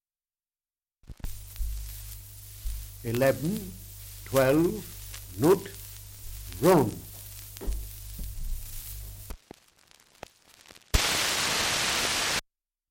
2 - Dialect recording in Kirkby Stephen, Westmorland
78 r.p.m., cellulose nitrate on aluminium
English Language - Dialects